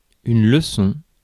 Ääntäminen
IPA : [ˈlɛ.s(ə)n]